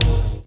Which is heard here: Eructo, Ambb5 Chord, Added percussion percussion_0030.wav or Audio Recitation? Added percussion percussion_0030.wav